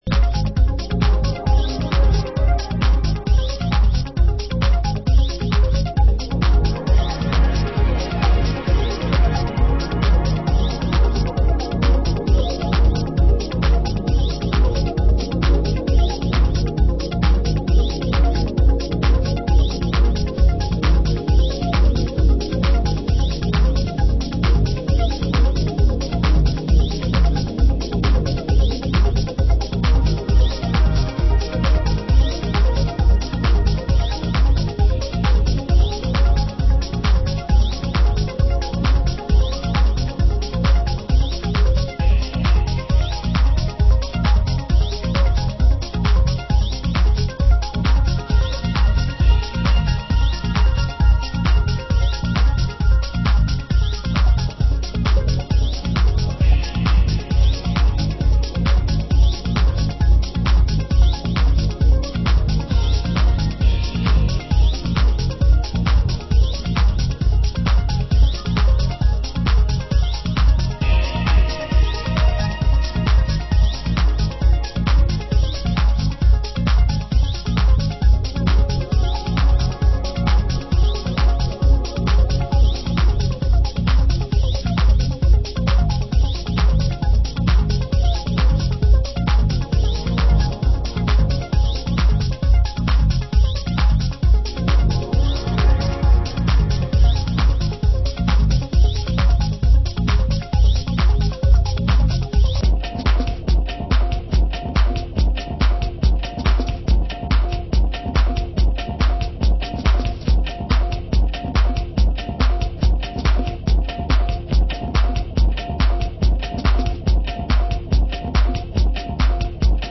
Genre: Detroit Techno